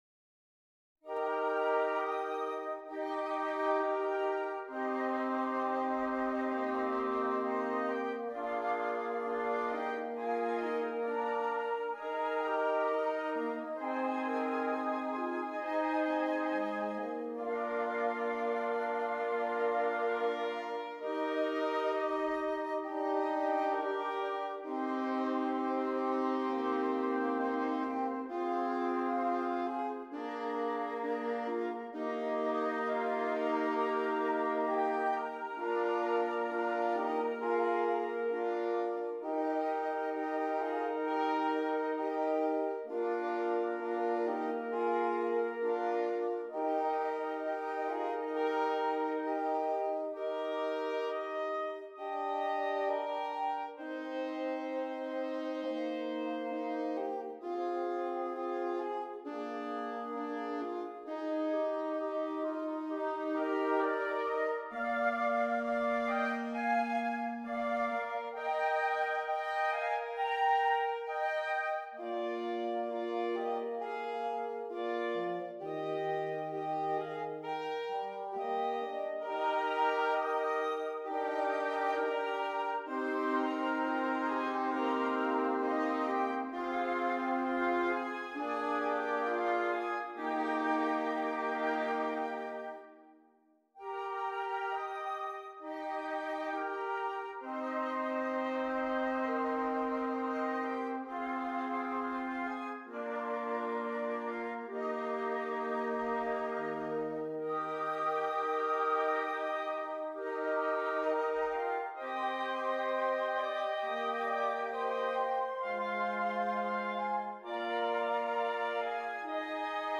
Interchangeable Woodwind Ensemble
Traditional Austrian Carol